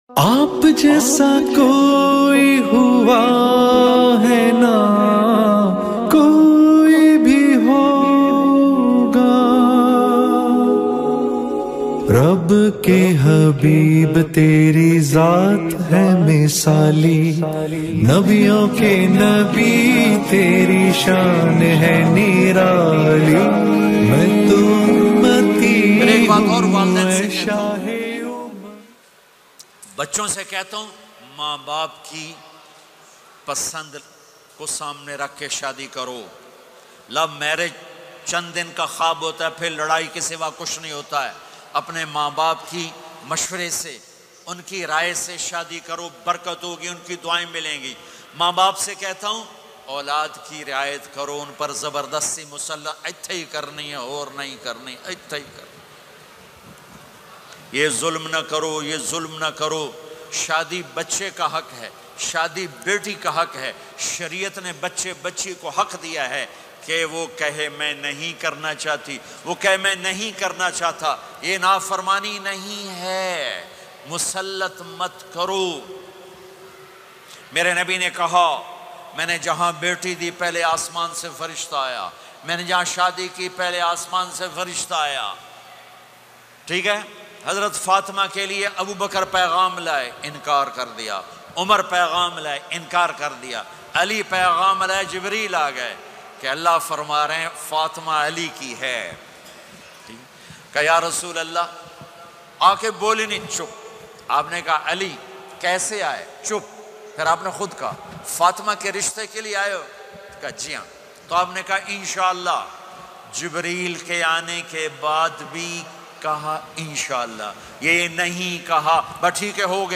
LOVE Marriage in Islam by Molana Tariq Jameel Latest Bayan mp3